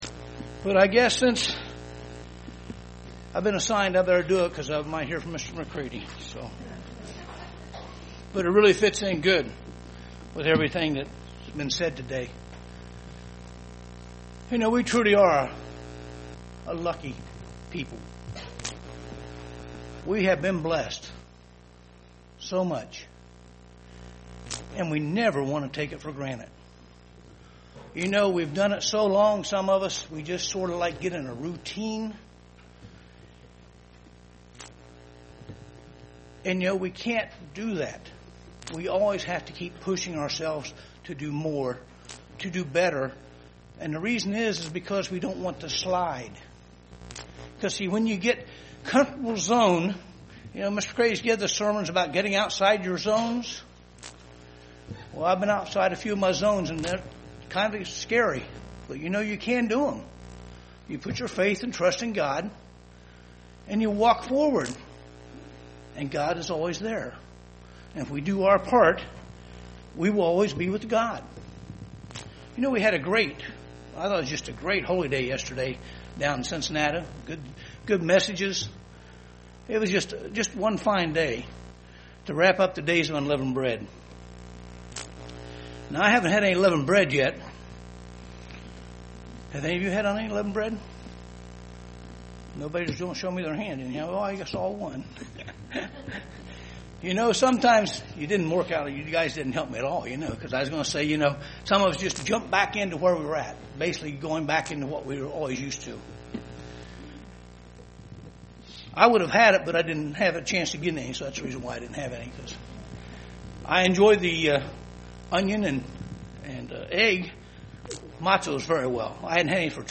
In this sermon we will look at Christ's parable of the sower for answers.